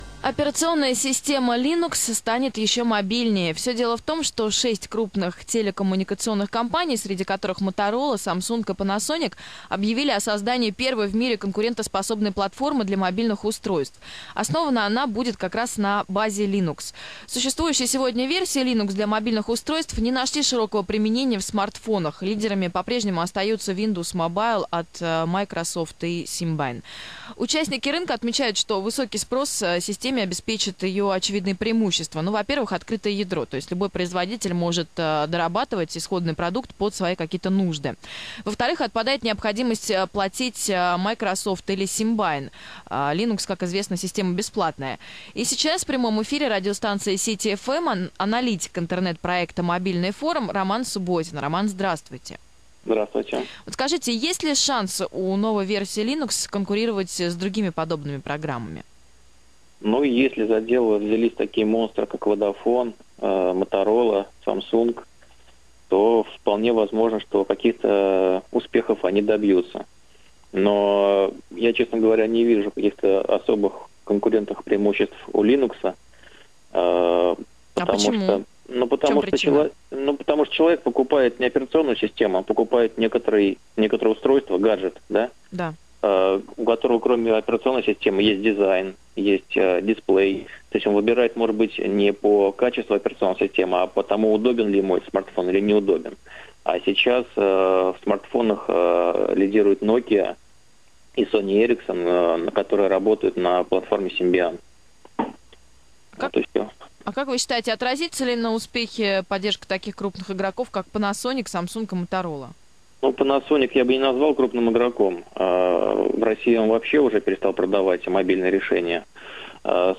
"Мобильный Форум" на радио: Комментарии для City-FM о начале продвижения крупными игроками мобильного рынка ОС Linux в смартфонах
Об этом - комментарий "Мобильного Форума" радиостанции City-FM.